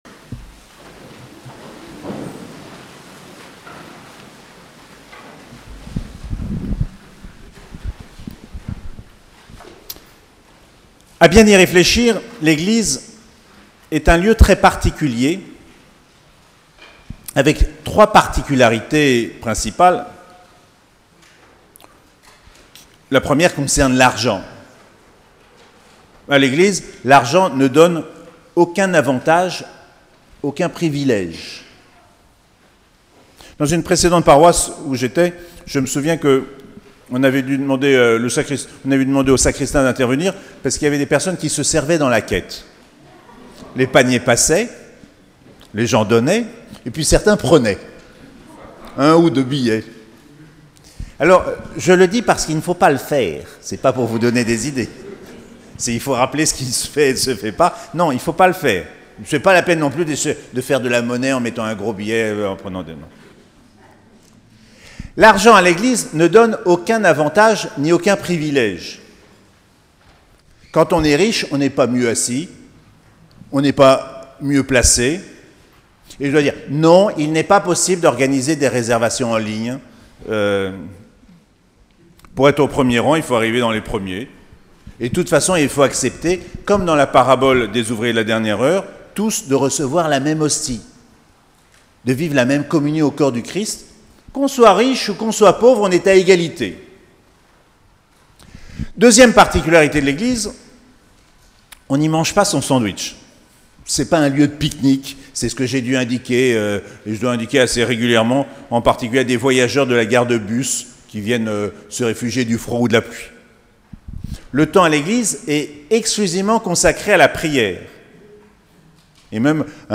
6ème dimanche du temps ordinaire - 17 février 2019